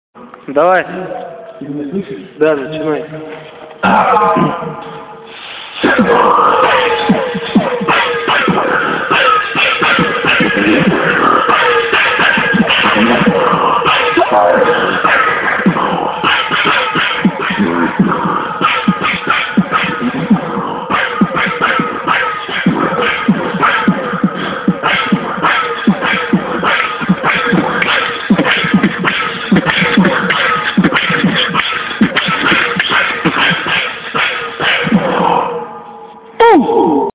Небольшаая запись...Тихо,эхо.
И так, небольшая запись была сделана вчера...Труба,длинной метров 30.
Да я сам не ожидал такого эффекта))Потом уже на записи спалили)))там получается я в начале трубы, телефон в конце может поэтому...хз))
Accord-echo__.mp3